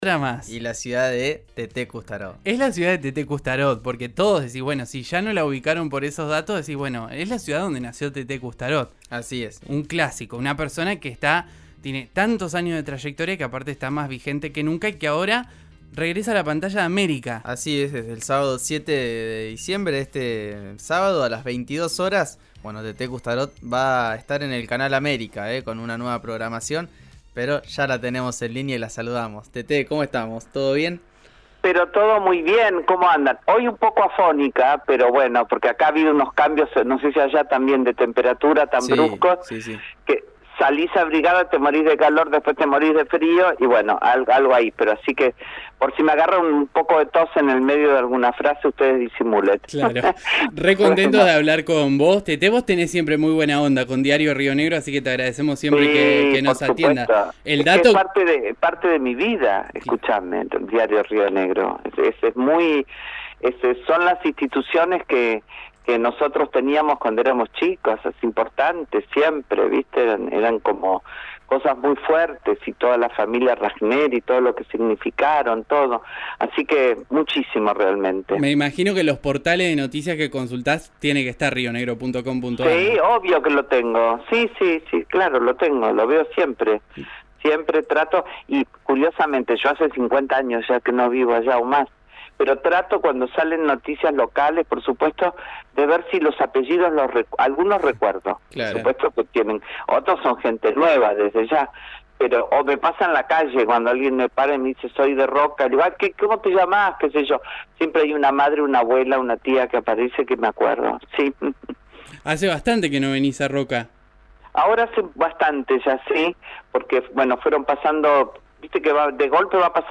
La conductora de TV regresa a la pantalla en el canal América y dialogó con RIO NEGRO RADIO sobre este proyecto, sobre su amor por General Roca y otras cosas más.
«No quería dejar de atenderlos. Me dijeron que me tenía que quedar en reposo y no usar la voz. Menos hablar por teléfono», detalló la conductora que supo ser la reina de la Fiesta Nacional de la Manzana.